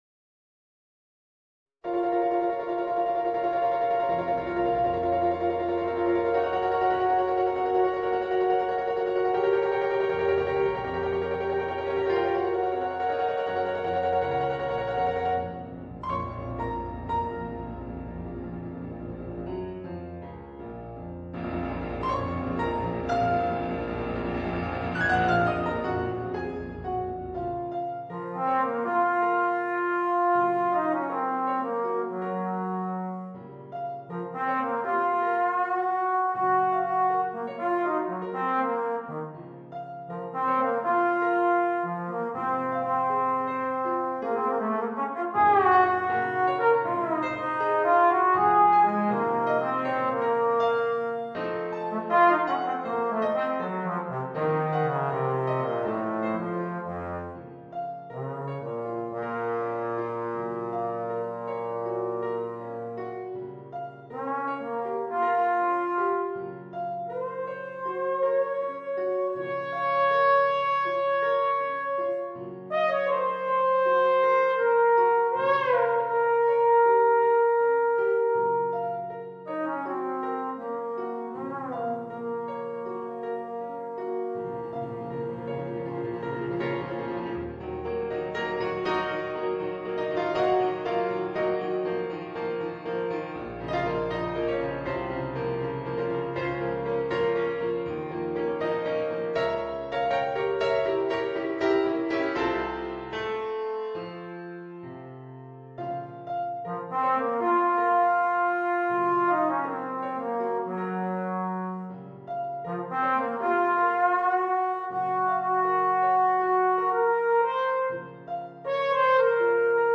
Voicing: Trombone w/ Audio